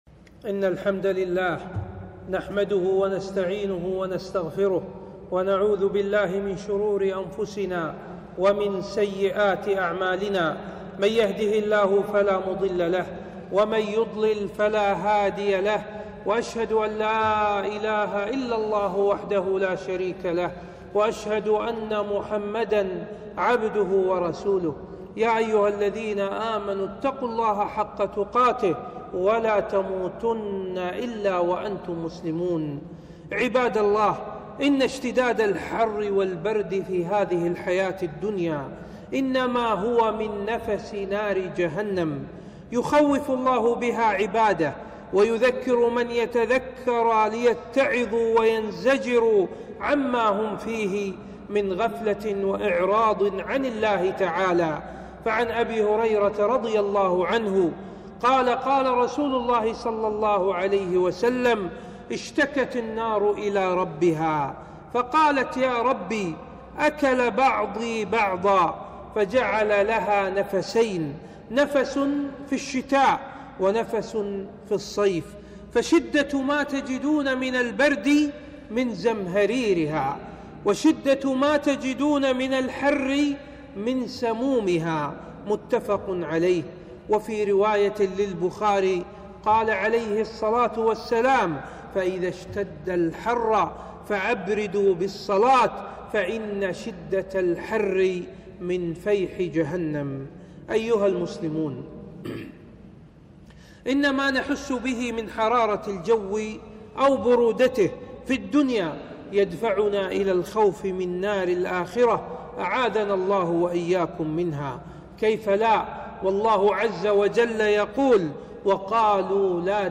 خطبة - اشتداد الحر من فيح جهنم